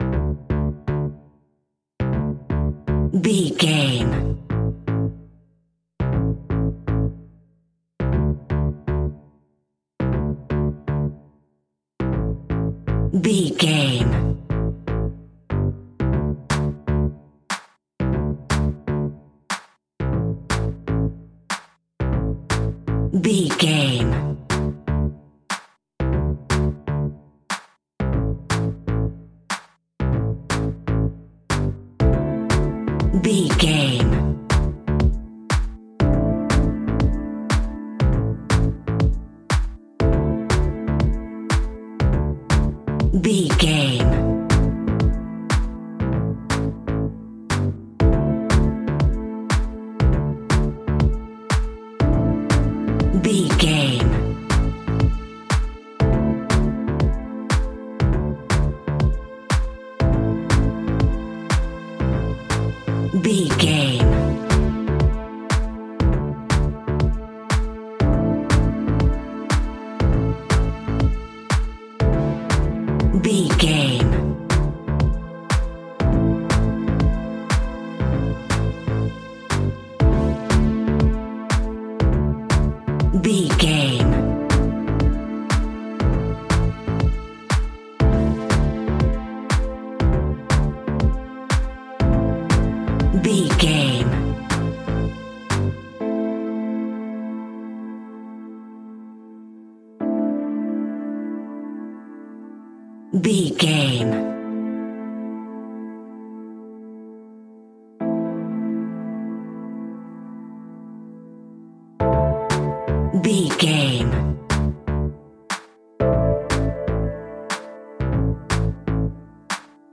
Aeolian/Minor
groovy
uplifting
driving
energetic
bass guitar
strings
electric piano
synthesiser
drum machine
funky house
upbeat